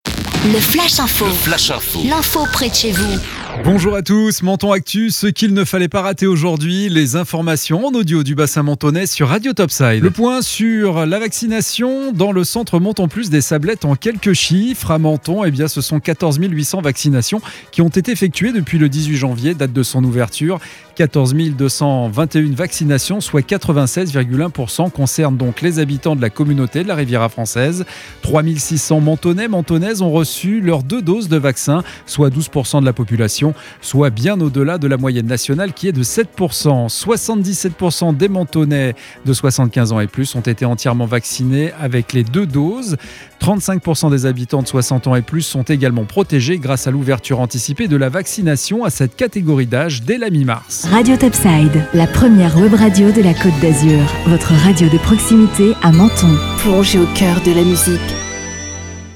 Menton Actu - Le flash info du lundi 22 avril 2021